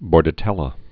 (bôrdə-tĕlə)